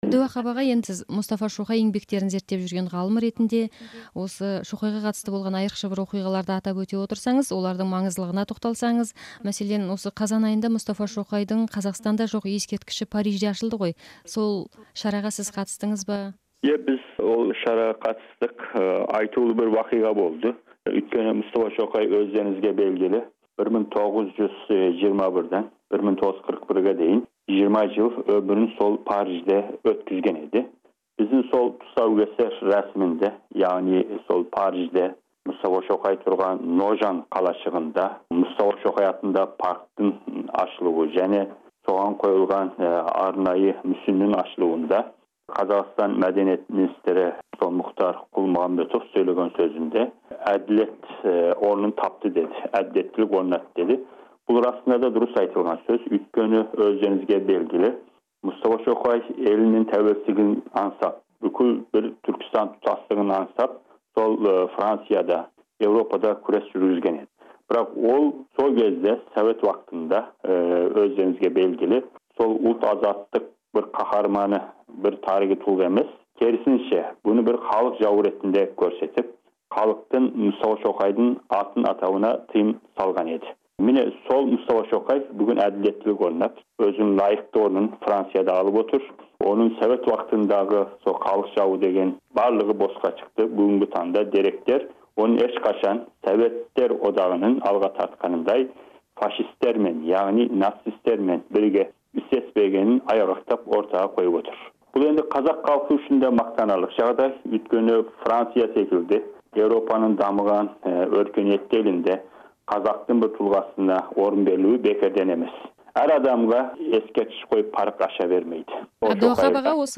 Сұхбат.